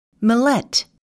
[məlét]